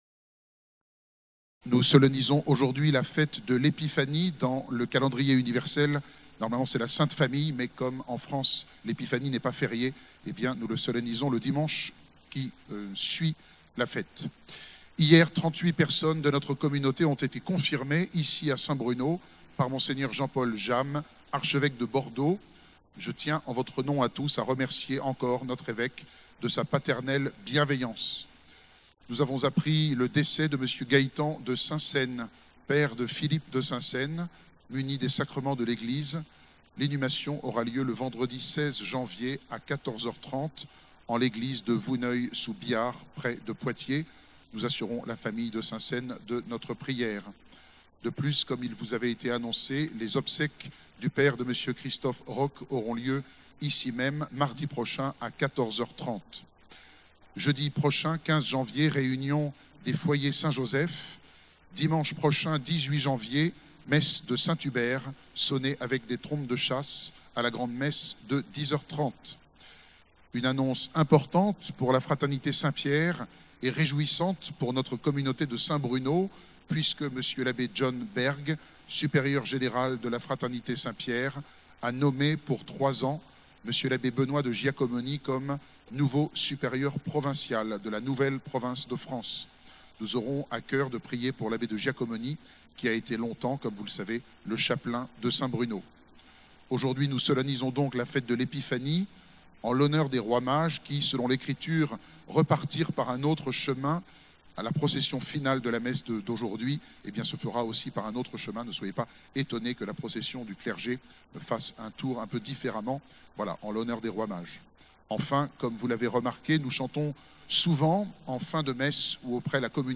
Annonces de la semaine